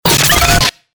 FX-1052-PHONE-BREAKER
FX-1052-PHONE-BREAKER.mp3